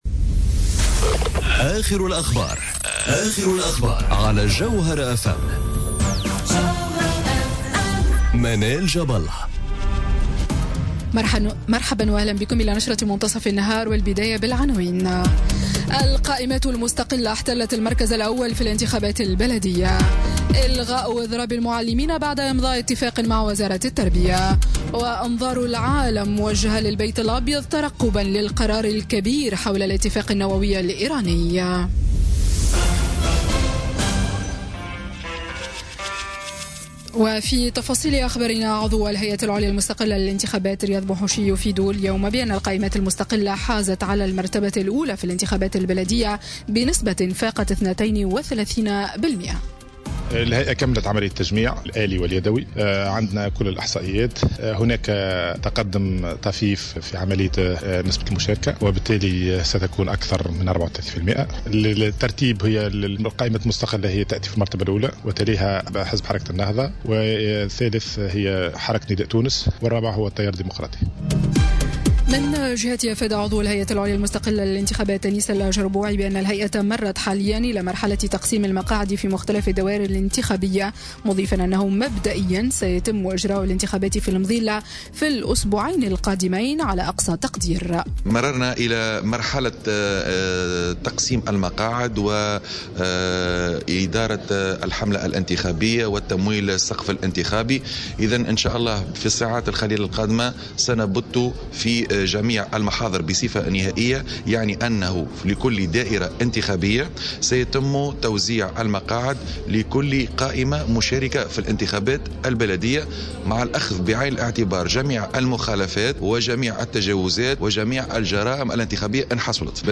journal info 12h00 du Mardi 8 Mai 2018